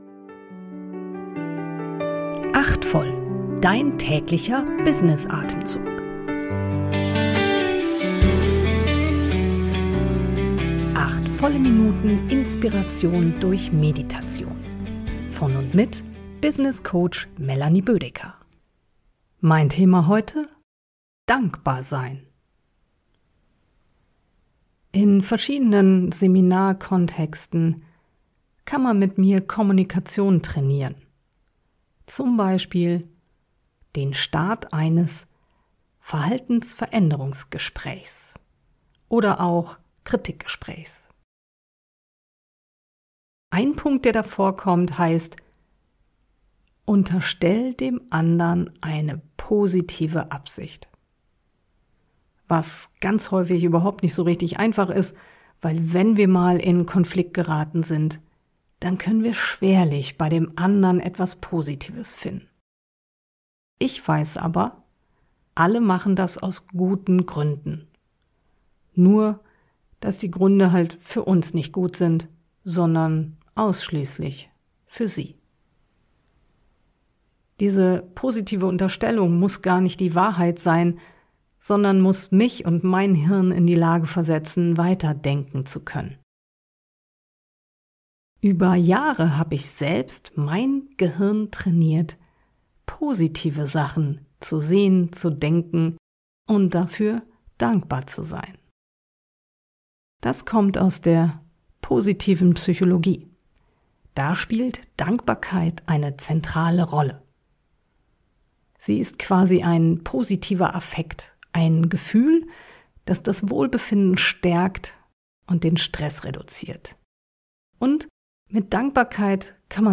kurze Erfrischung durch eine geleitete Kurz-Meditation.